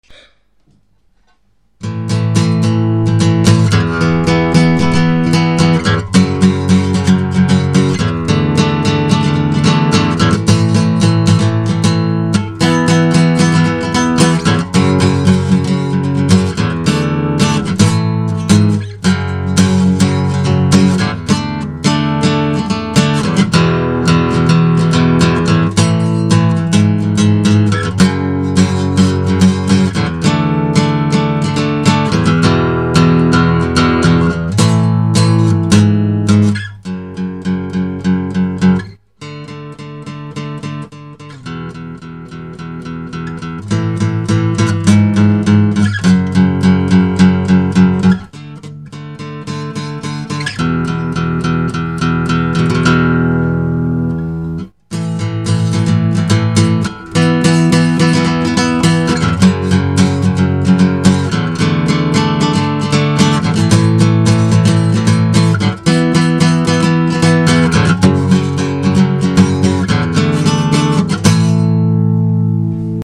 • Stop, w którym jest 85% miedzi i 15% cyny. Struny z owijką z takiego materiału łączą wspaniałą charakterystykę brzmienia strun z owijką typu 80/20, z większym sustain (dźwięk dłużej wybrzmiewa), co przypomina cechy strun fosforowo-brązowych